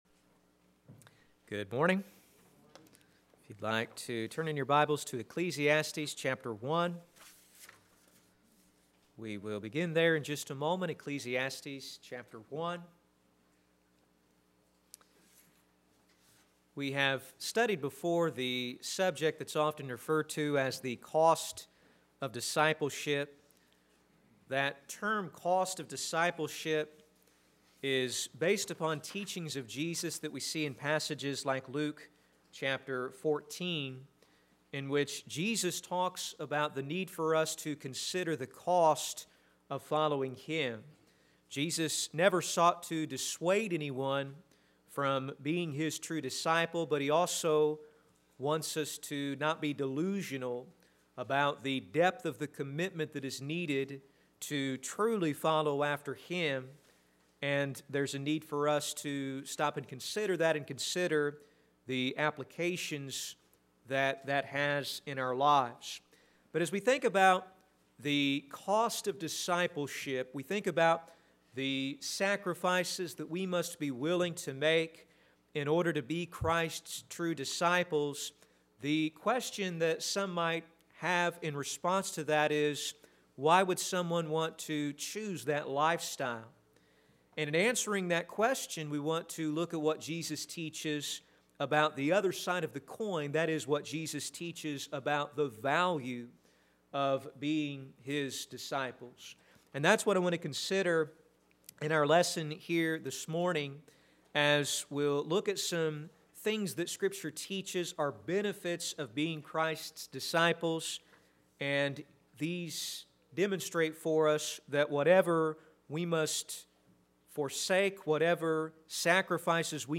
Sermons
Service: Sunday AM